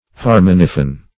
Search Result for " harmoniphon" : The Collaborative International Dictionary of English v.0.48: Harmoniphon \Har*mon"i*phon\ (h[aum]r*m[o^]n"[i^]*f[o^]n), n. [Gr.